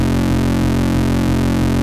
BUCHLA F2.wav